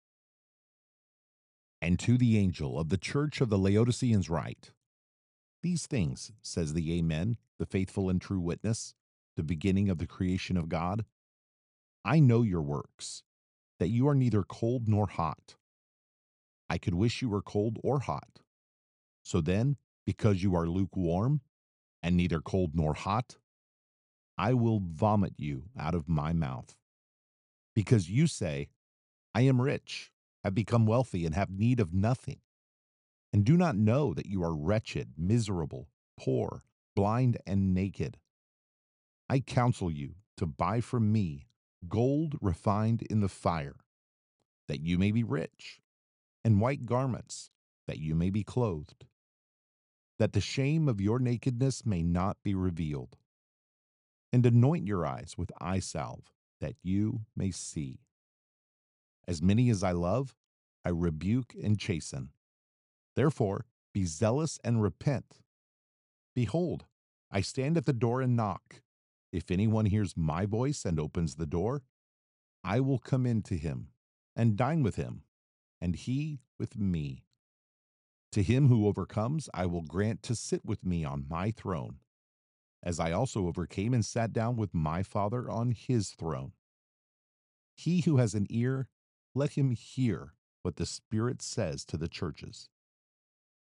Audiobook - "Strong" (Excerpt)